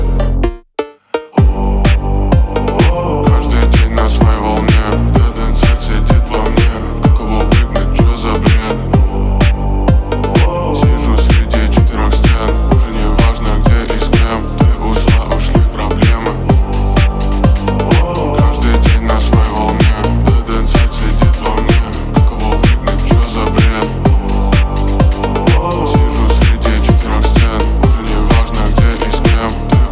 Жанр: Рэп и хип-хоп / Русские